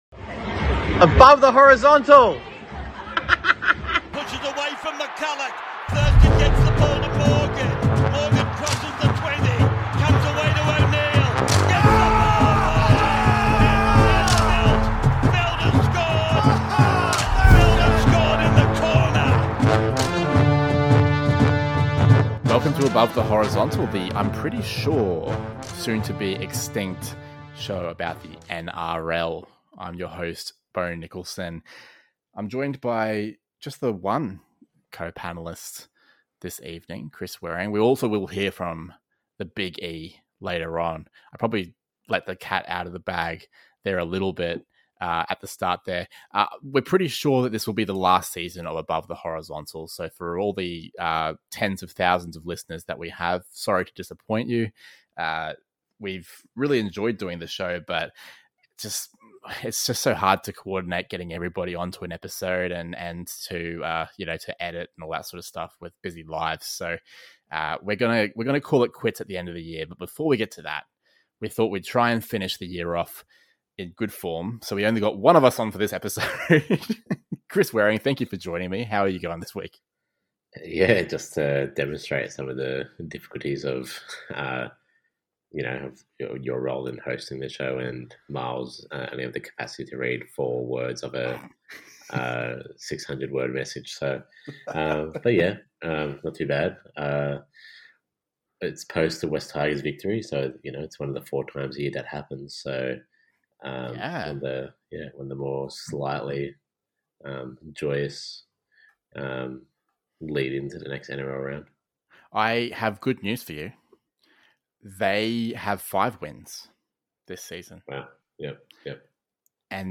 A Conversation podcast about Rugby League